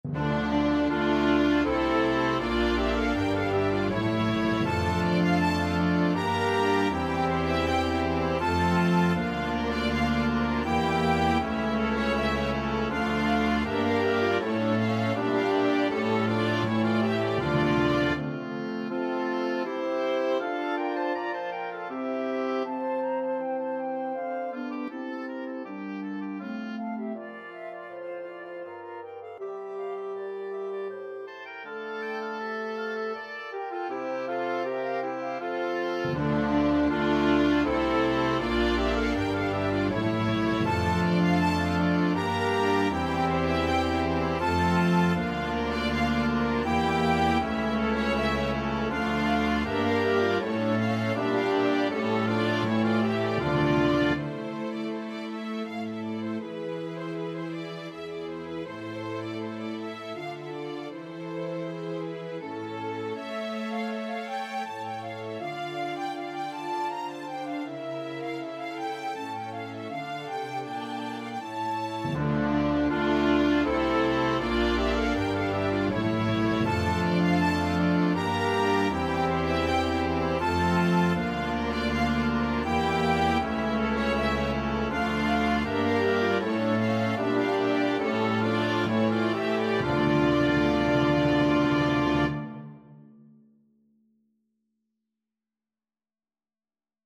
Orchestration
2 Flutes, 2 Oboes, 2 Clarinets in Bb, 2 Bassoons
2 Horns in F (or Eb), 2 Trumpets in Bb, 2 Trombones, Timpani
Strings (Violin 1, Violin 2, Viola or Violin 3, Cello, Bass)